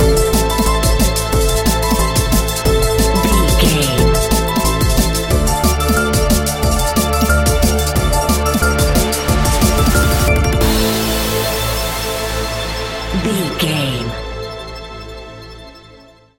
Aeolian/Minor
Fast
aggressive
dark
futuristic
industrial
frantic
synthesiser
drum machine
electric piano
sub bass
instrumentals